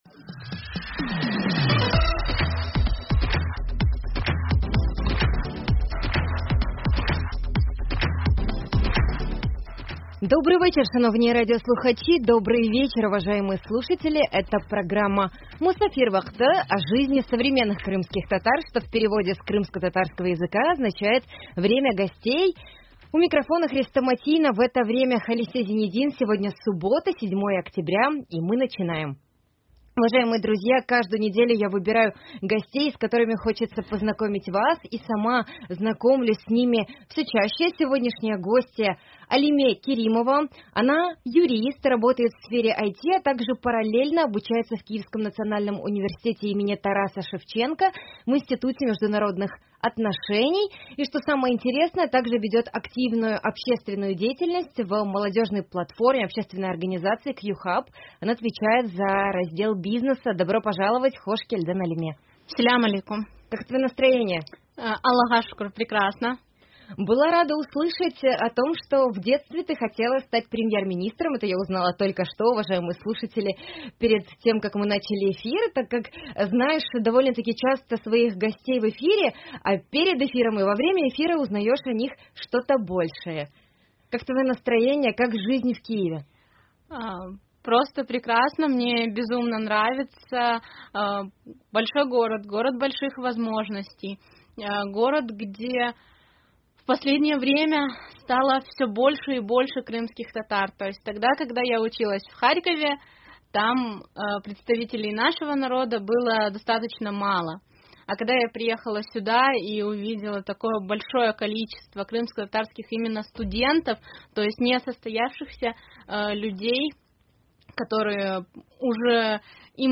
7 октября в эфире Радио Крым.Реалии передача о жизни современных крымских татар «Мусафир вакъты».